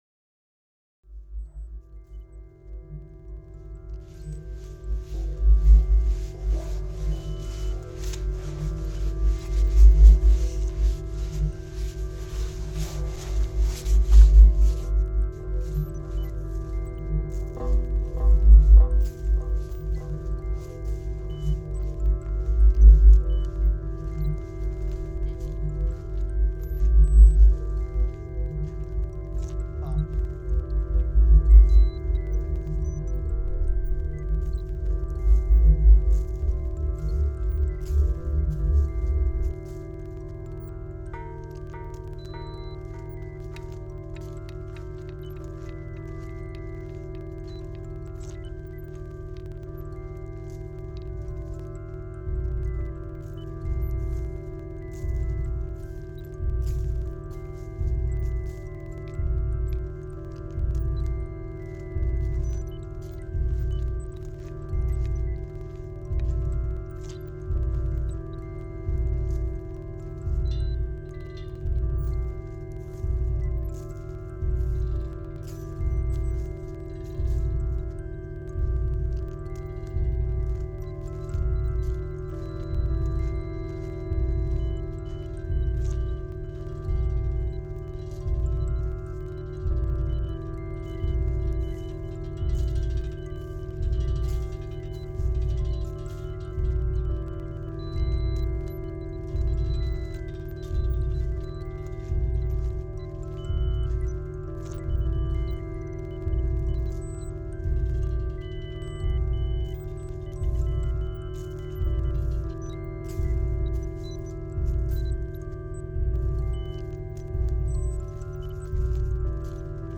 introspective and spatial ambient, of the dark kind